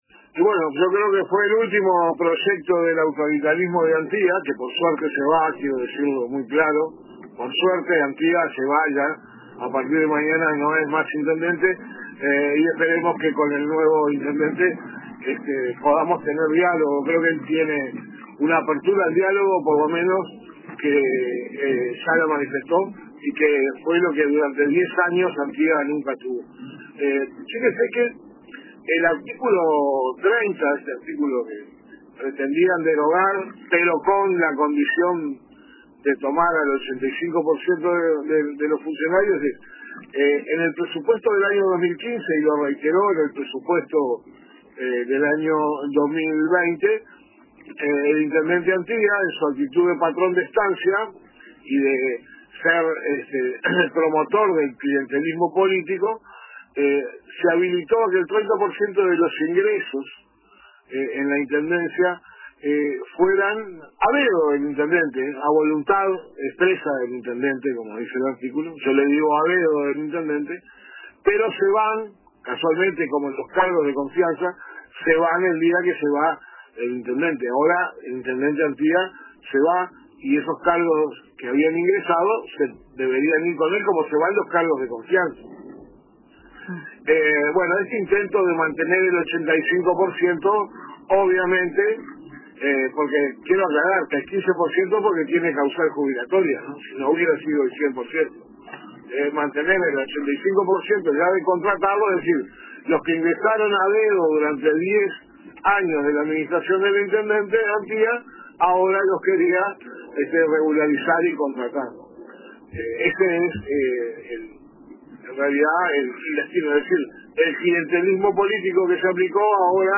Entrevistado en el programa La Tarde de RBC, Antonini calificó el proyecto como “el último acto de autoritarismo de Antía” y sostuvo que se trata de una maniobra para “premiar el clientelismo político ejercido durante los últimos diez años”.